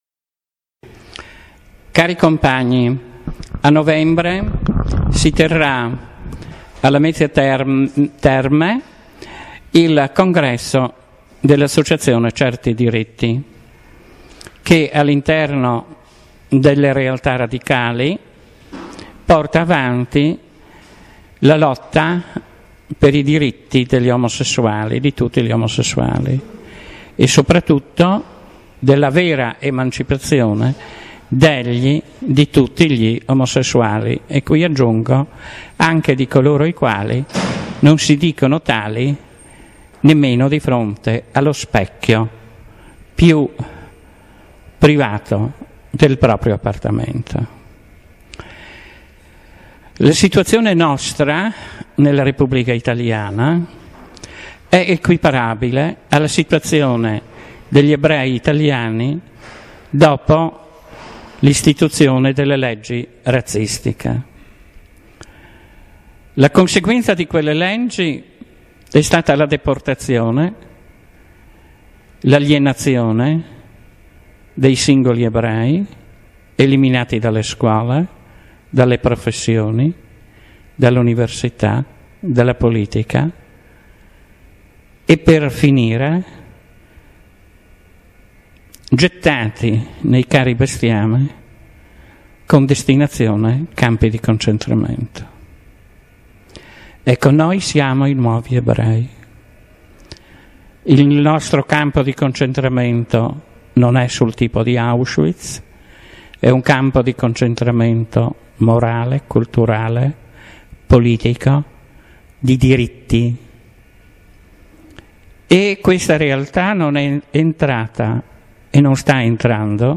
Intervista realizzata in occasione del Comitato Nazionale di Radicali Italiani 03-04-05 ottobre 2014, Roma presso la sede del Partito Radicale.